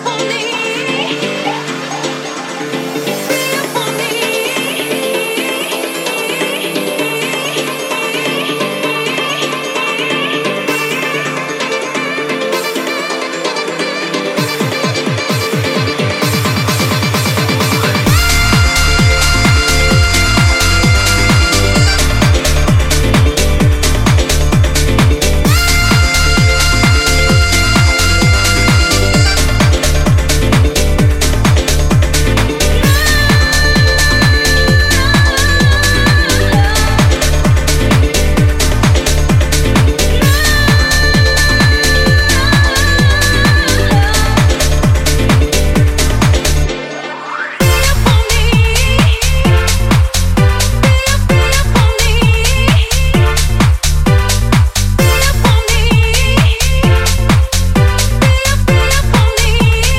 90sハウス古典を現代的かつピークタイム向けにアップデートしたかのうような